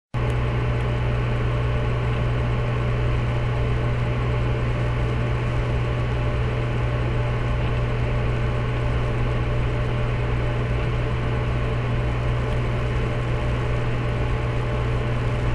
p.s 소음 녹음한 것
1. 팬컨트롤러 최저 소음 (소음 제로)